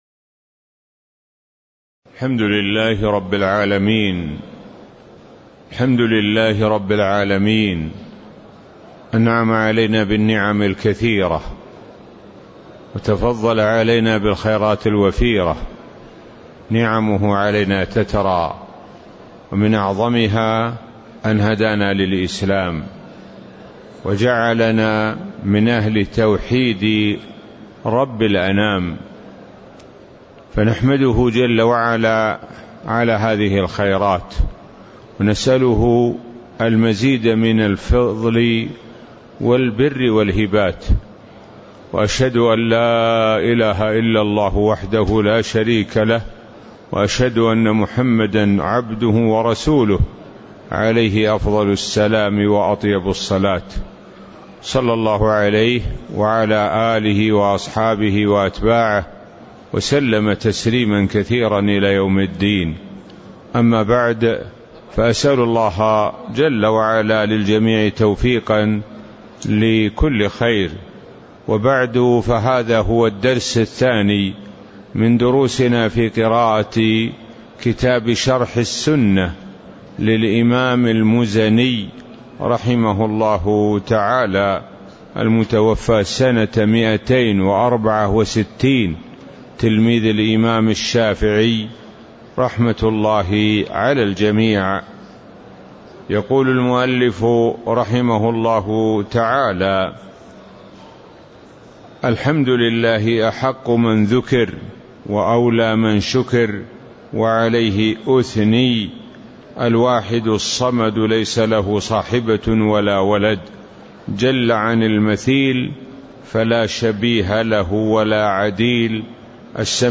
تاريخ النشر ١٦ ذو القعدة ١٤٤٣ هـ المكان: المسجد النبوي الشيخ: معالي الشيخ د. سعد بن ناصر الشثري معالي الشيخ د. سعد بن ناصر الشثري قوله: الحمدلله أحق من ذكر وأولى من شكر (02)